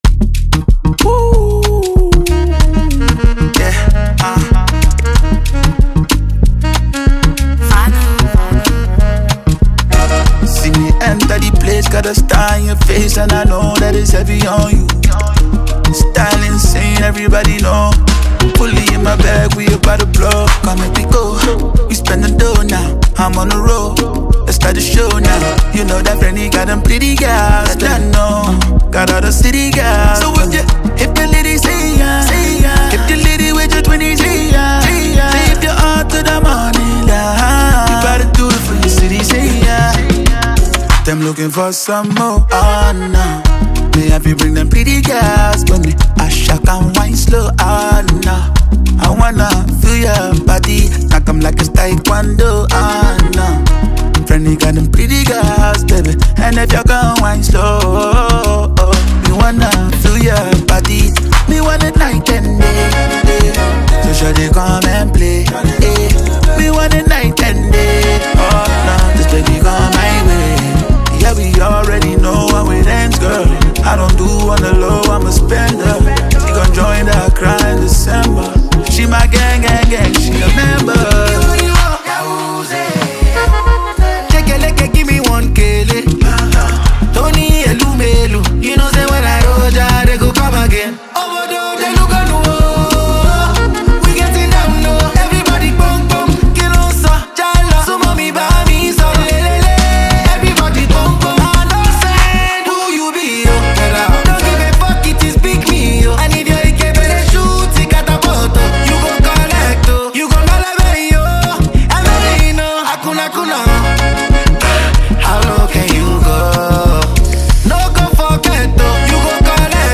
This catchy tune